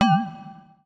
UIClick_Marimba Metal Wobble 02.wav